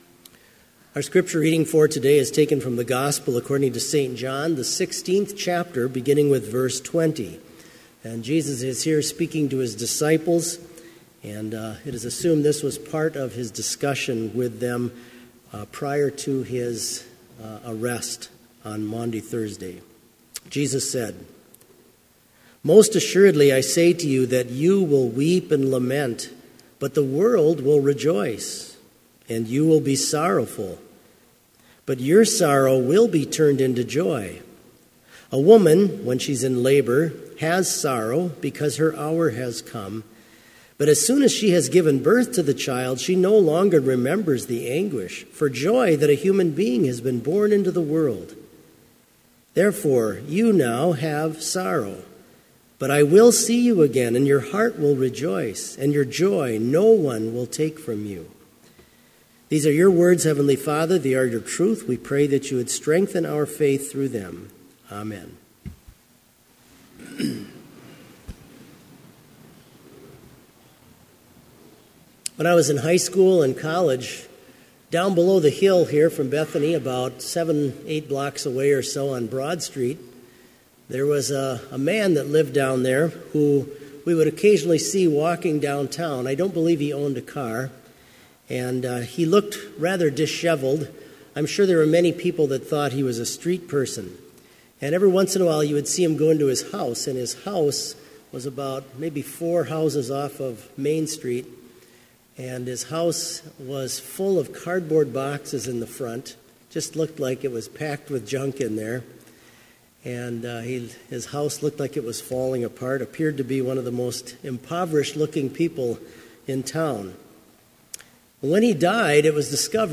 Complete service audio for Chapel - May 8, 2017